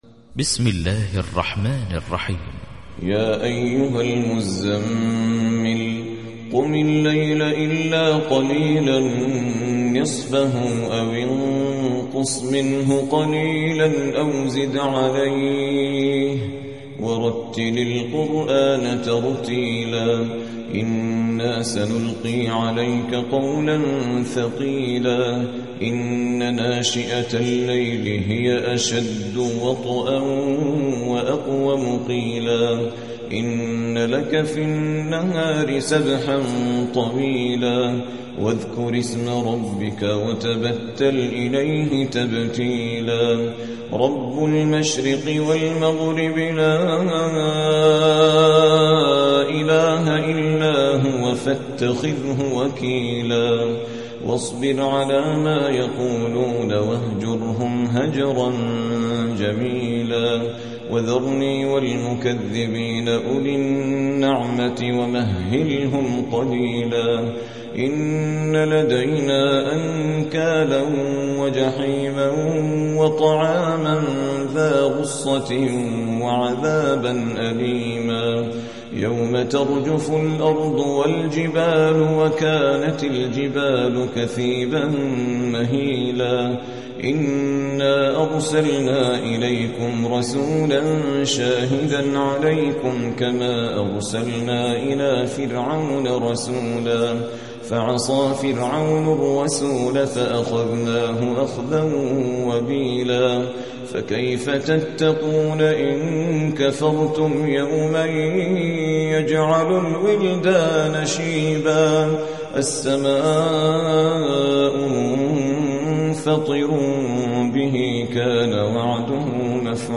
73. سورة المزمل / القارئ